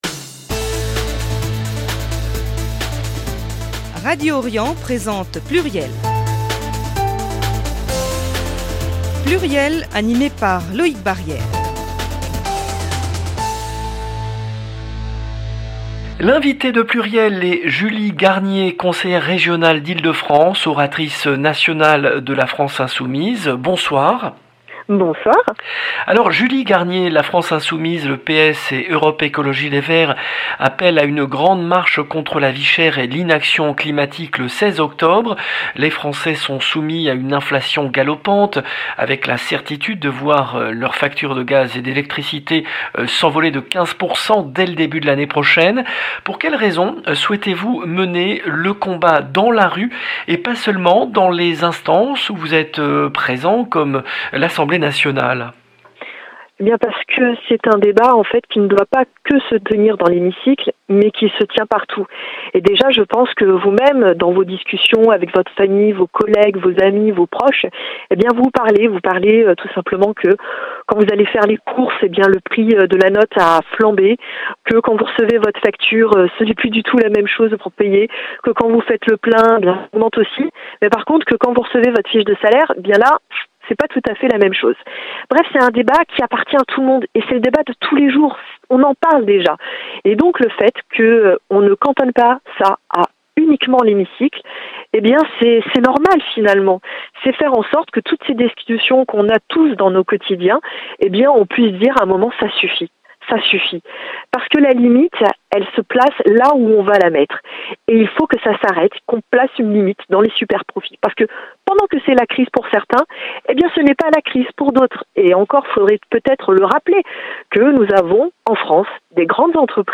le rendez-vous politique du mercredi 28 septembre 2022 L’invitée de PLURIEL était Julie Garnier , conseillère régionale d’Ile-de-France, oratrice nationale de la France Insoumise.